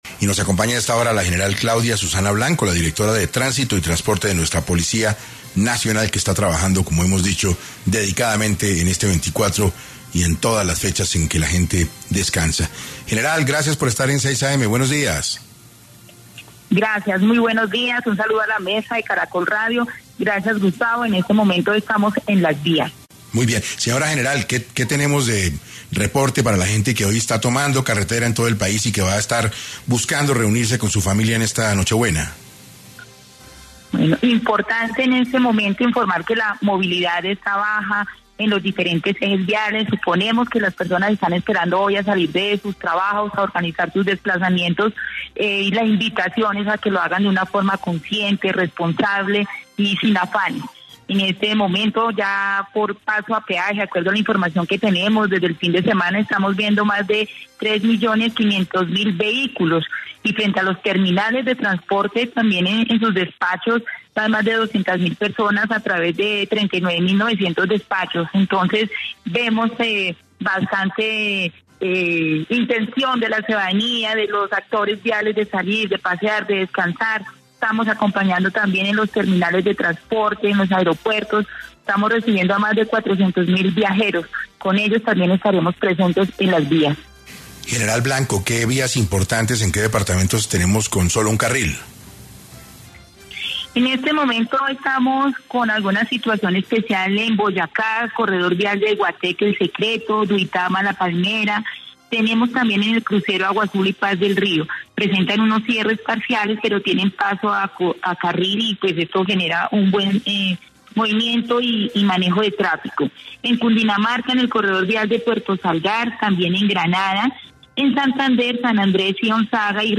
La General Claudia Susana Blanco, directora de Tránsito y Transporte de la Policía Nacional, estuvo en ‘6AM’ de Caracol Radio y compartió las recomendaciones que se deben tener si piensa viajar por estos días.